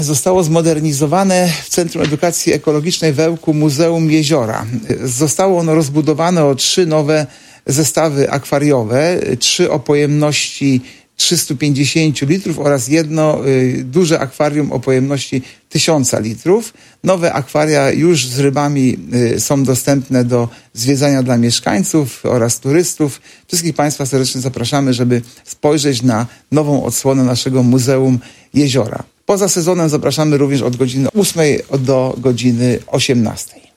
Artur Urbański, zastępca prezydenta miasta, zachęca mieszkańców i przyjezdnych do obejrzenia tej nietuzinkowej ekspozycji.